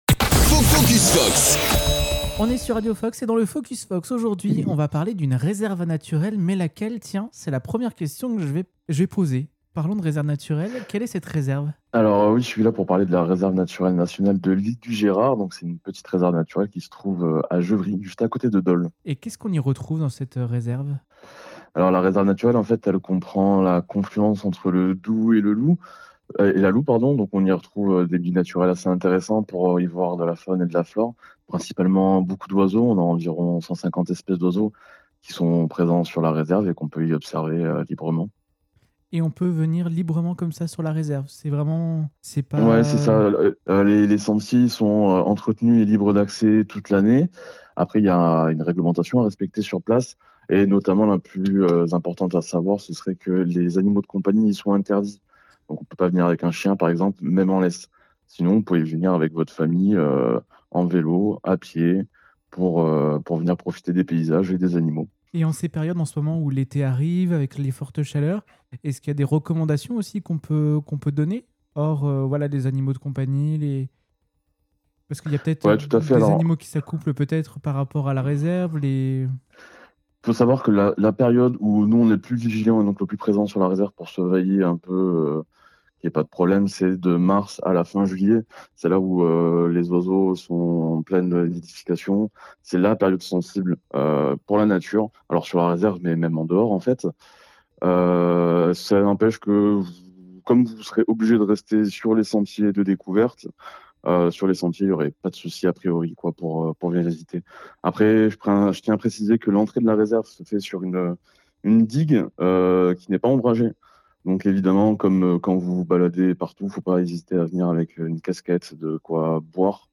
Dans ce nouvel épisode, on part à la découverte de la Réserve naturelle de l’Île du Girard, à Gevry, avec un guide de Dole Environnement, joint par téléphone.